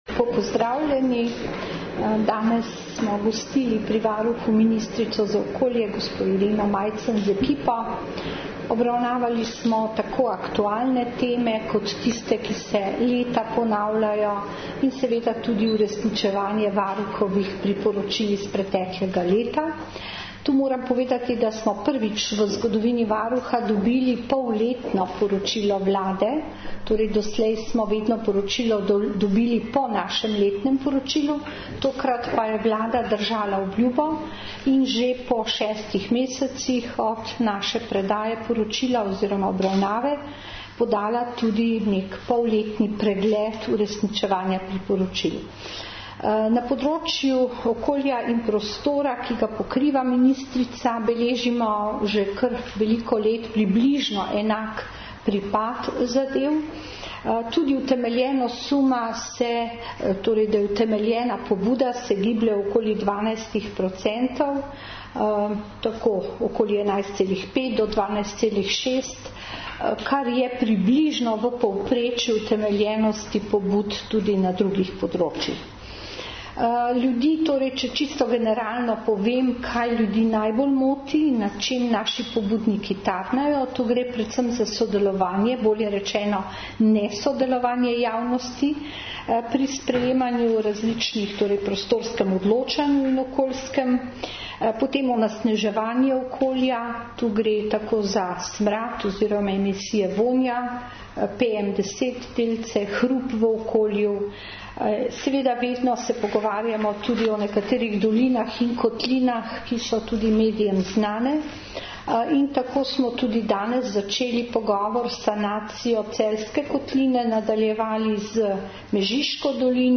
Po srečanju sta varuhinja in ministrica podali skupno izjavo za javnost.